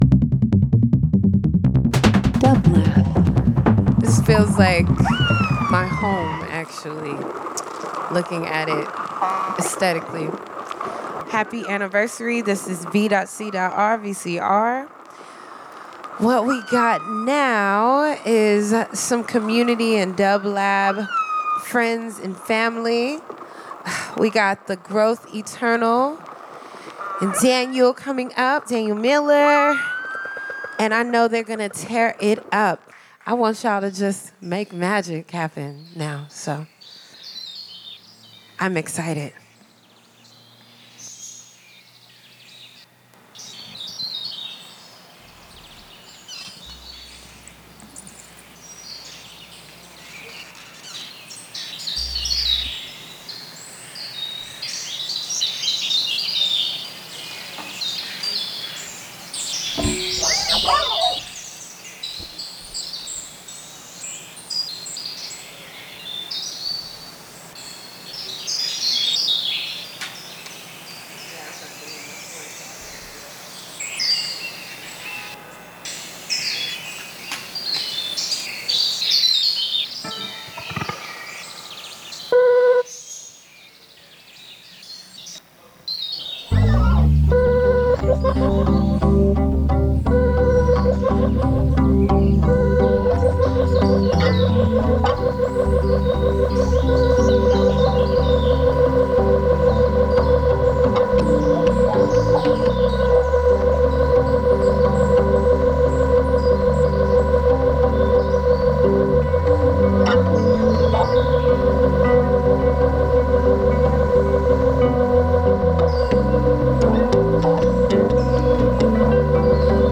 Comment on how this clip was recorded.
LIVE FROM THE LOFT @ PORTER STREET STUDIO – OCT 11, 2025 Funk/Soul Live Performance R&B Trip Hop